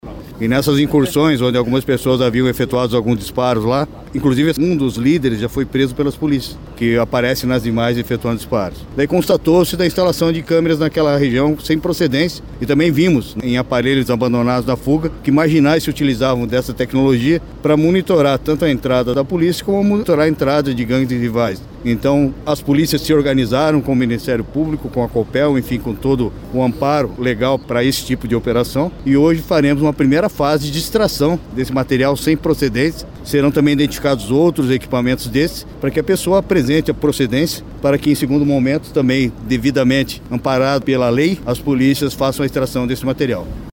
O secretário de Segurança Pública do Paraná (Sesp), Hudson Teixeira, disse que os equipamentos da região serão analisados e os que estão instalados de forma clandestina serão retirados.